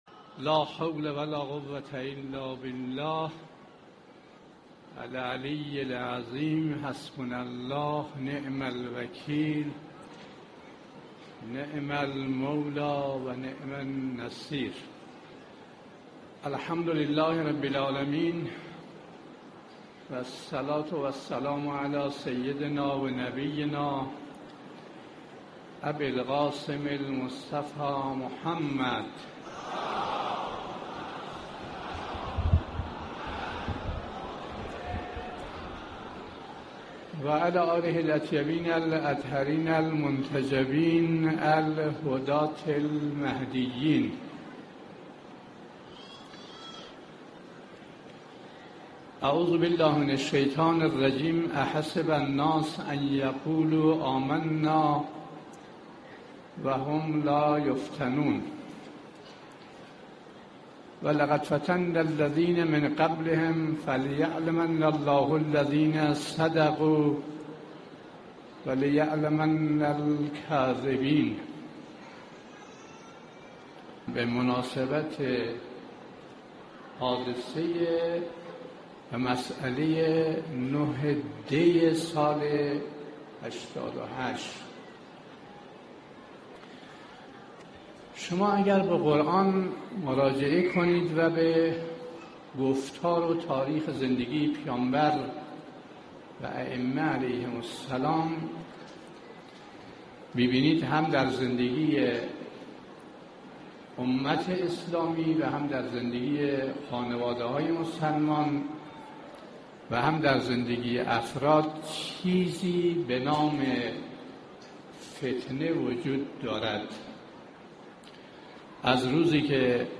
صوت سخنرانی مذهبی و اخلاقی